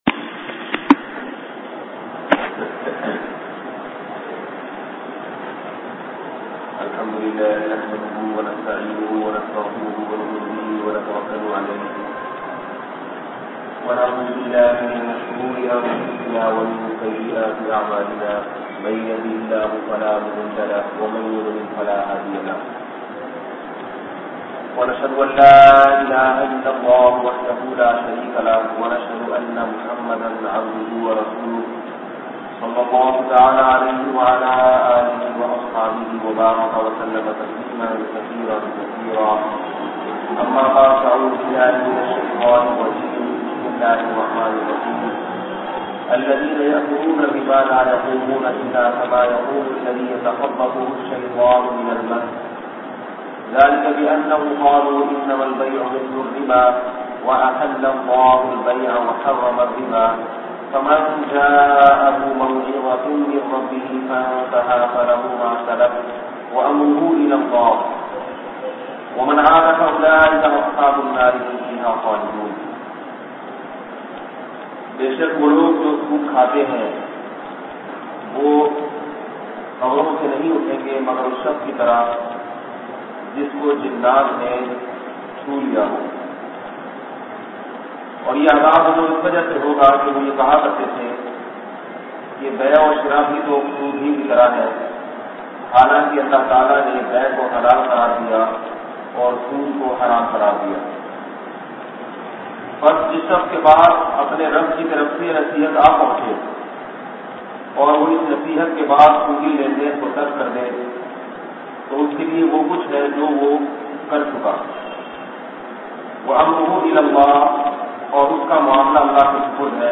Sood Ko Halal Samaj Nay Walo Ka Injaam bayan mp3 play online & download.